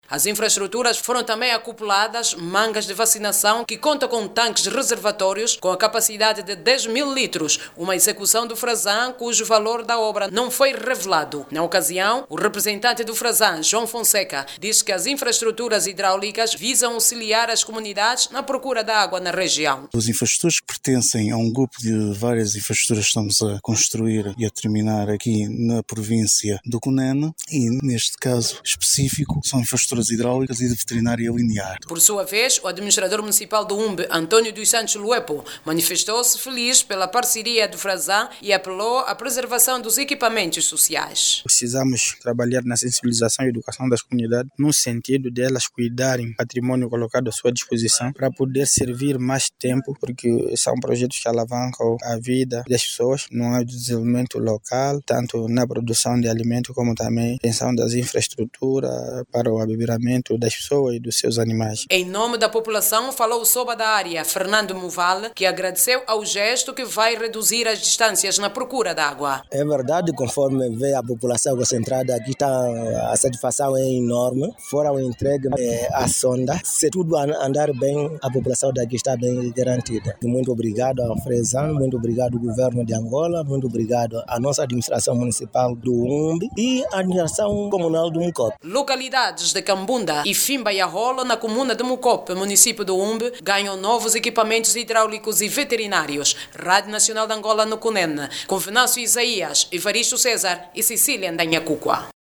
A população do município do Humbe, província do Cunene, beneficia do abastecimento de água potável com a existência de recentemente de dois furos de agua, infraestrutura que vai fazer, com que os cidadãos deixam de percorrer longas distancias em busca de agua. Clique no áudio abaixo e ouça a reportagem